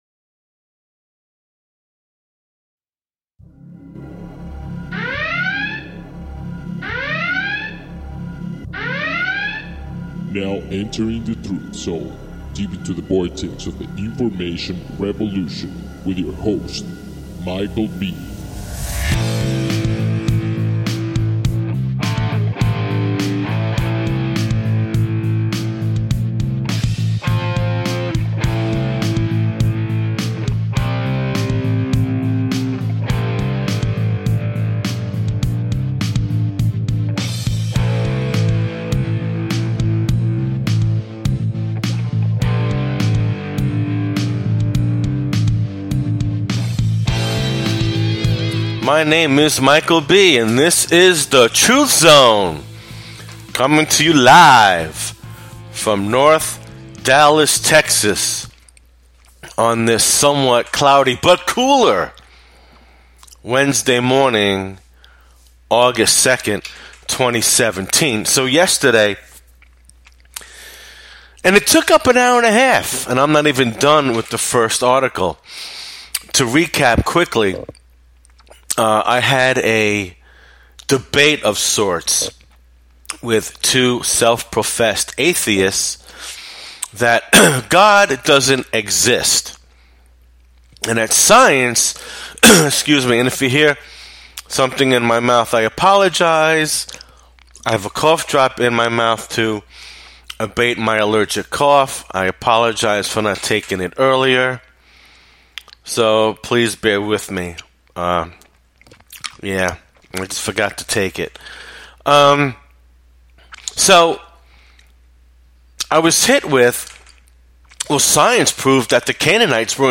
Talk Show Episode, Audio Podcast, Fact Checking the Bible and How old is the New World Order on , show guests , about Jehovah,God,satan,Lies,Bible,Spirit,Faith in History,New World Order, categorized as Education,History,Military,News,Philosophy,Politics & Government,Religion,Society and Culture,Spiritual